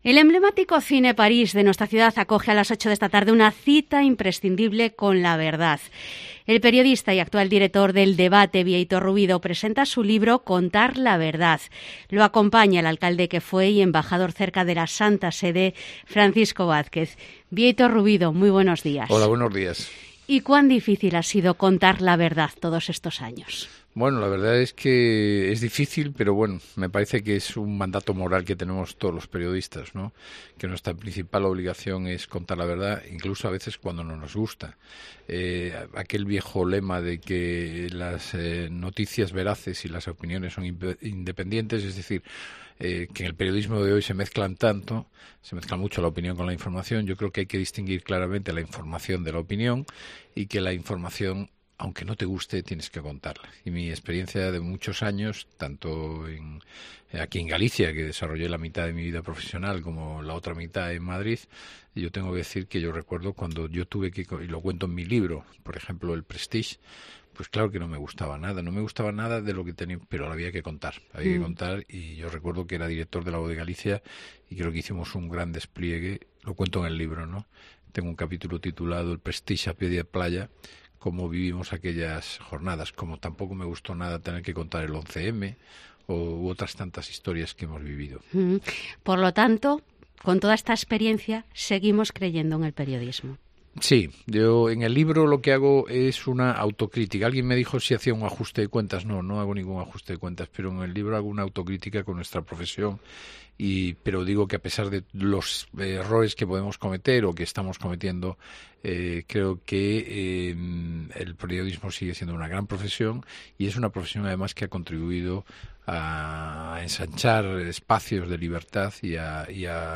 Entrevista a Bieito Rubido por su libro 'Contar la Verdad' en COPE Coruña